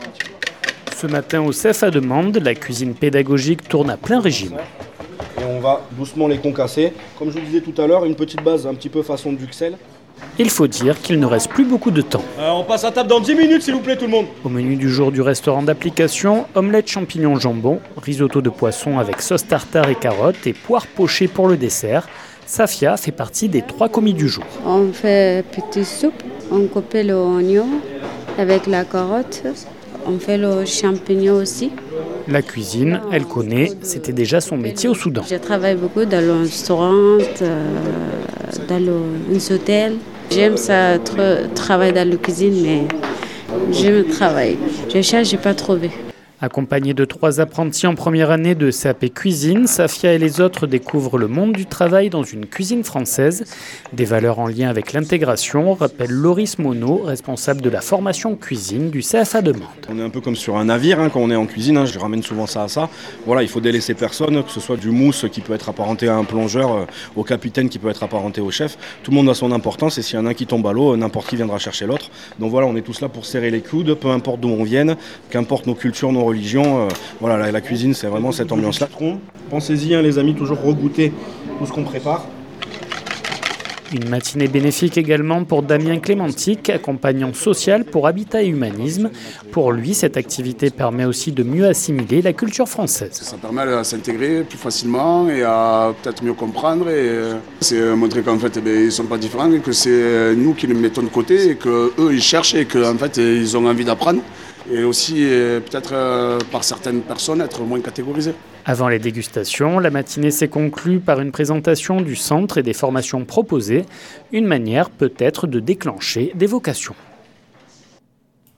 Jeudi dernier, trois adultes accompagnés par l’association Habitat et Humanisme ont enfilé leur tablier pour découvrir le monde de la cuisine française. De quoi, peut-être, créer des vocations. 48FM s’est rendu sur place.
Reportage